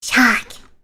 Worms speechbanks
Jump2.wav